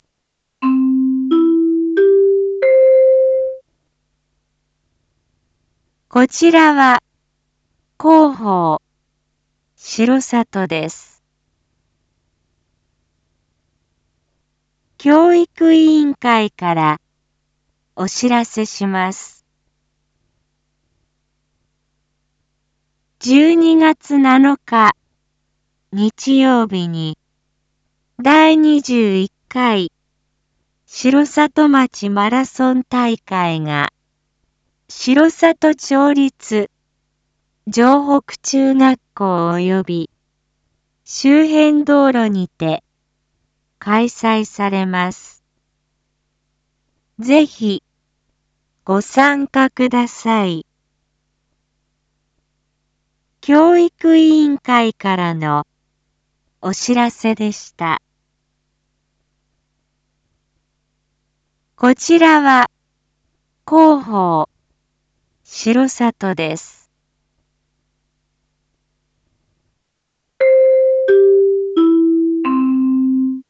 Back Home 一般放送情報 音声放送 再生 一般放送情報 登録日時：2025-11-10 19:01:14 タイトル：第21回城里町マラソン大会開催⑥ インフォメーション：こちらは広報しろさとです。